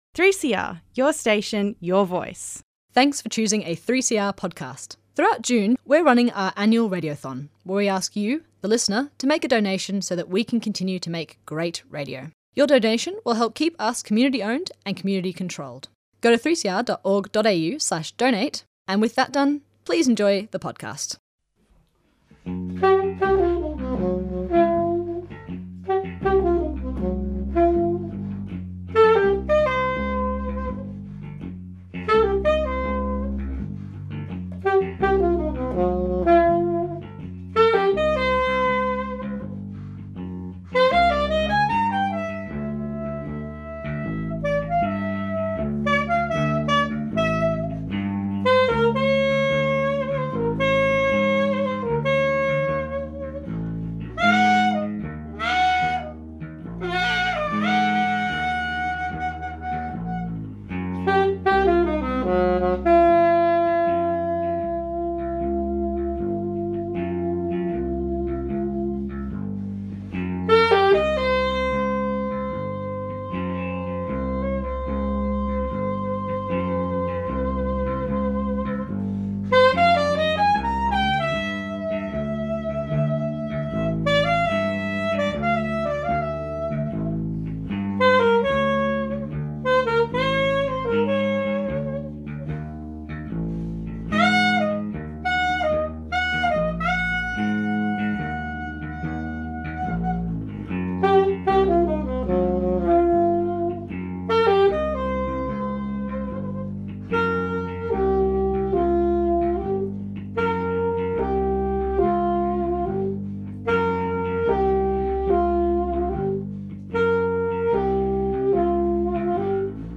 an eclectic selection of tracks